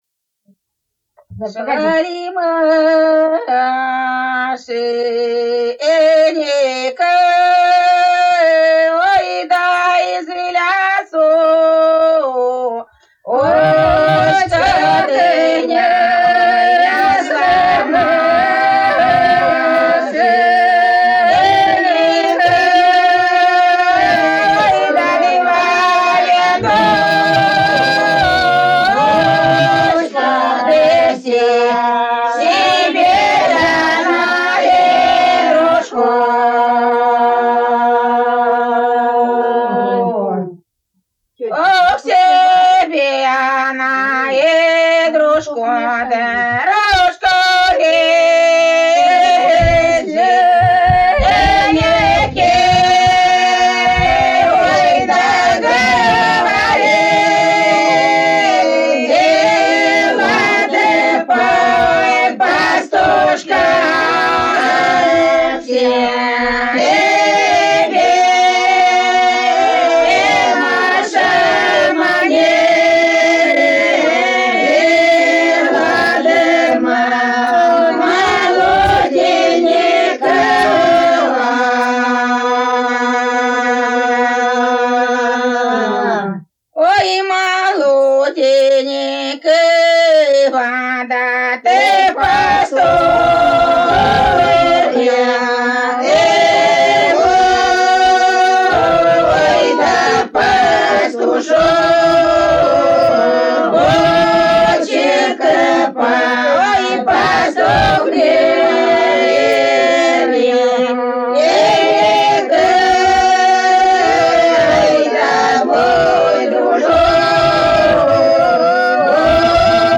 Народные песни Касимовского района Рязанской области «Шла ли Машенька», лирическая на Троицу.